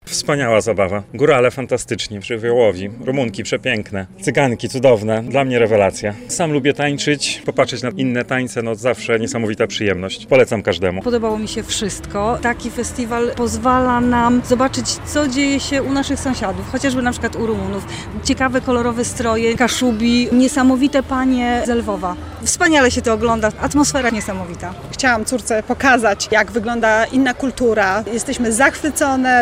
Różnorodność tradycji, muzyki i tańca. Finałowy koncert Festiwalu Kultur w Biłgoraju
Koncert finałowy w wykonaniu artystów z Rumunii, Ukrainy, Polski i aktorów z Teatru Żydowskiego z Warszawy zakończył tegoroczny międzynarodowy Festiwal Kultur w Biłgoraju. Trzydniowe wydarzenie to przypomnienie wielokulturowości miasta nad Ładą.